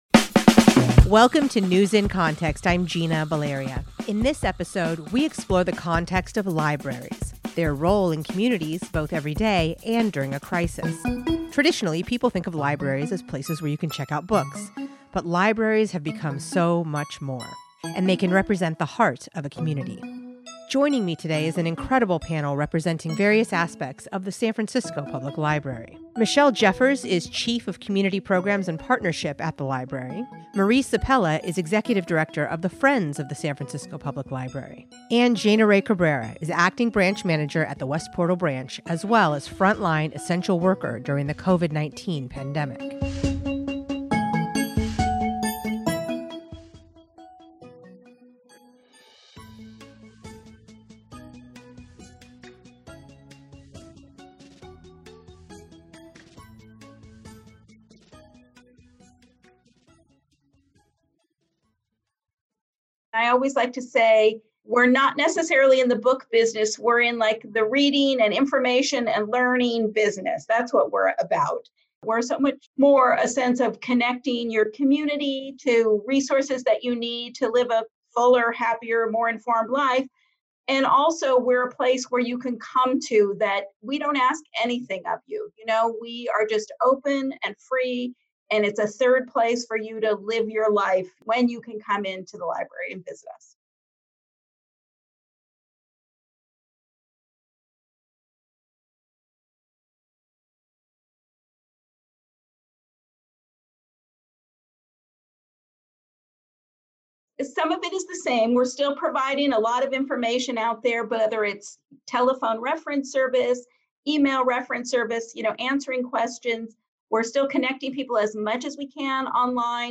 In this episode, we explore the context of libraries and their role in communities – both everyday… and during a crisis. Joining me is an incredible panel representing various aspects of the San Francisco Public Library.